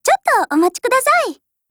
贡献 ） 协议：Copyright，其他分类： 分类:少女前线:MP5 、 分类:语音 您不可以覆盖此文件。
MP5_LOADING_JP.wav